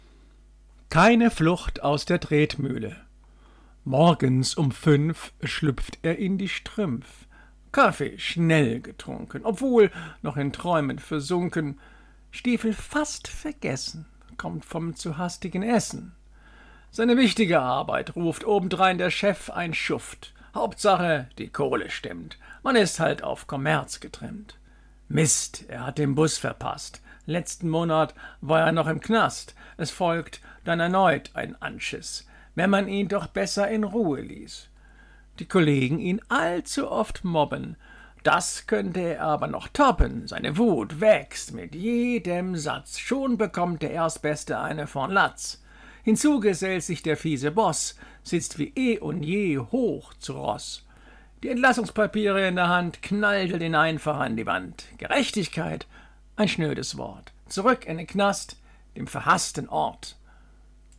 Gedichte – rezitiert
Insofern möchte ich hierbei so manche Gedichte für euch rezitieren.